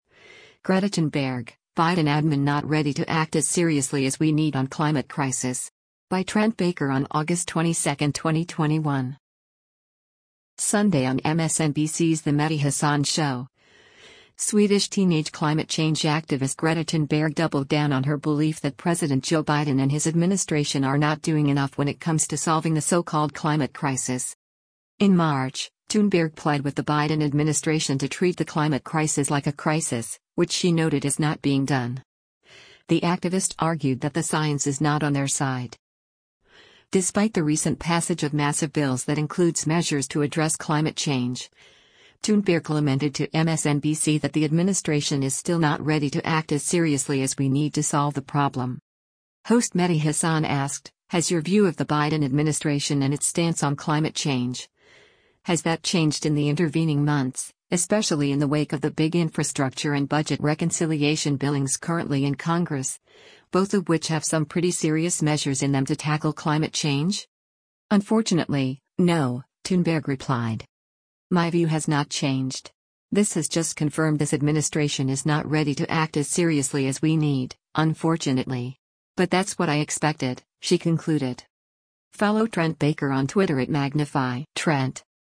Sunday on MSNBC’s “The Mehdi Hasan Show,” Swedish teenage climate change activist Greta Thunberg doubled down on her belief that President Joe Biden and his administration are not doing enough when it comes to solving the so-called climate crisis.
Host Mehdi Hasan asked, “Has your view of the Biden administration and its stance on climate change — has that changed in the intervening months, especially in the wake of the big infrastructure and budget reconciliation billings currently in Congress, both of which have some pretty serious measures in them to tackle climate change?”